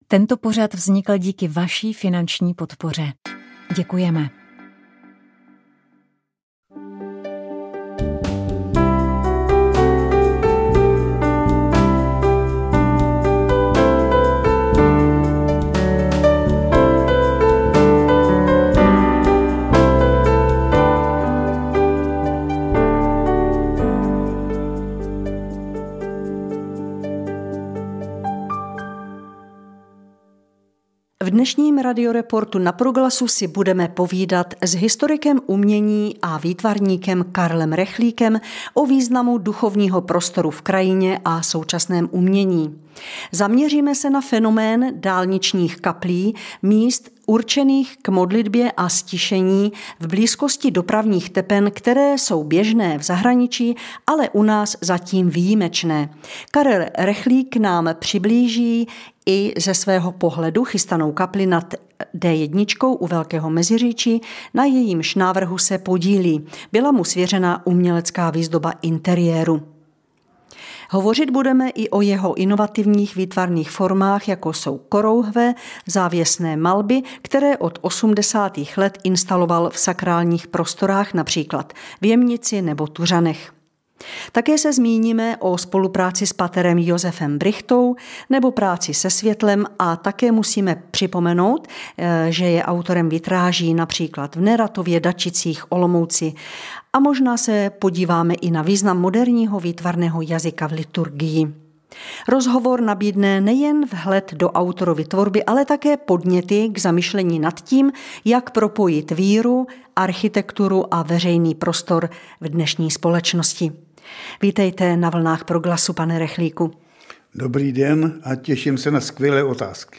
Náš host zmíní i význam duchovního prostoru v krajině a současném umění. Zaměřili jsme se na fenomén dálničních kaplí – míst určených k modlitbě a ztišení v blízkosti dopravních tepen, které jsou běžné v zahraničí, ale u nás zatím výjimečné.